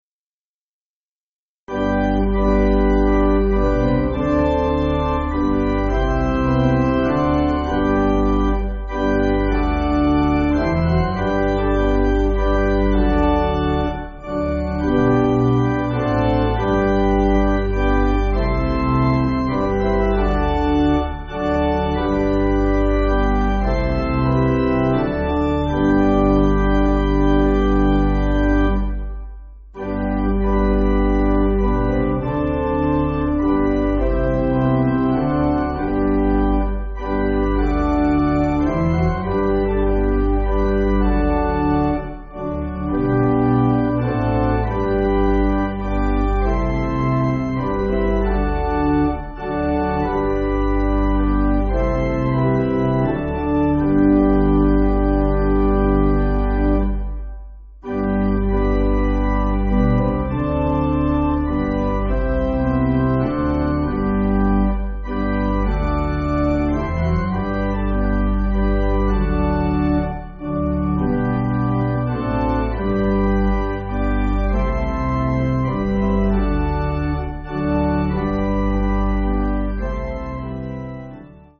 Organ
(CM)   5/G